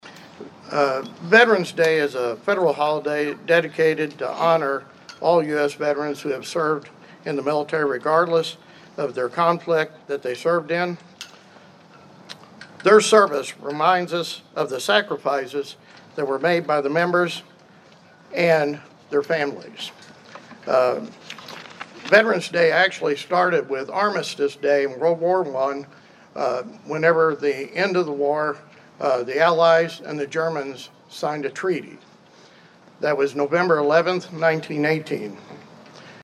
It was the First Anniversary of the downtown Vandalia Veteran’s Memorial Park, and it was well represented with a ceremony and then a Veteran’s Day parade through downtown Vandalia on Saturday.